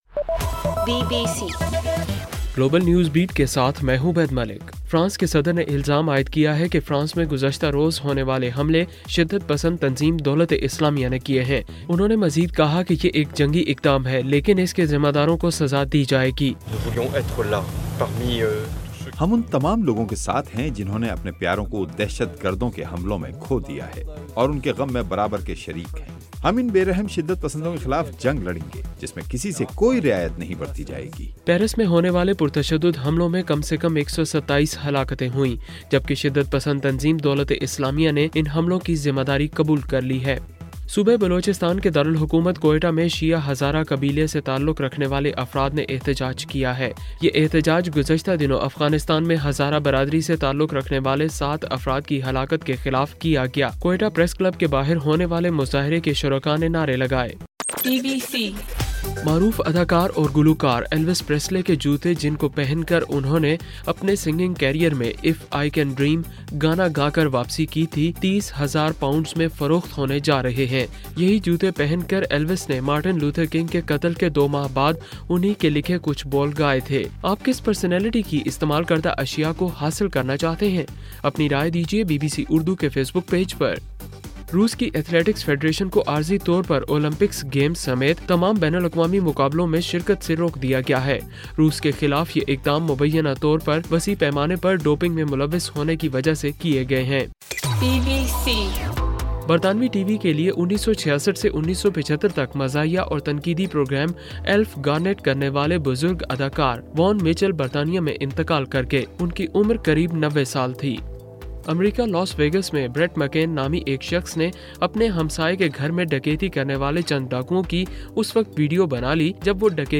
نومبر 14: رات 8 بجے کا گلوبل نیوز بیٹ بُلیٹن